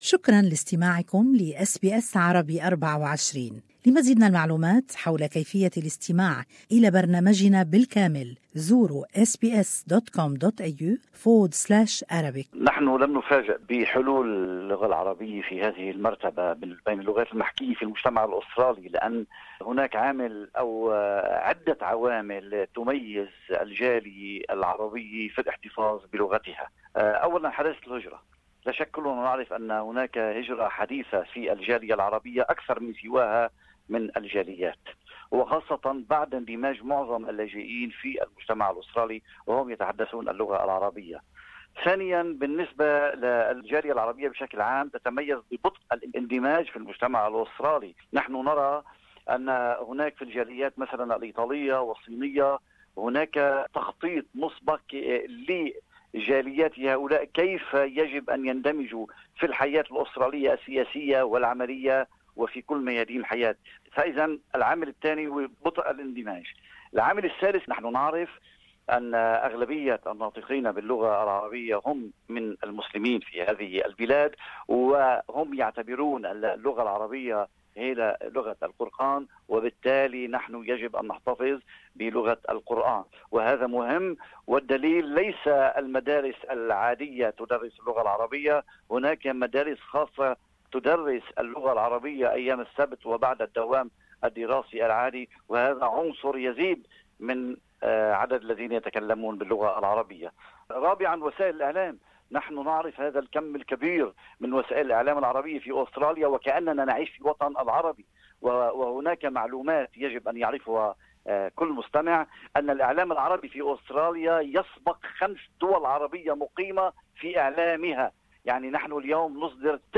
According to the 2016 Census results, Arabic came to be the third common language in Australia. More in this interview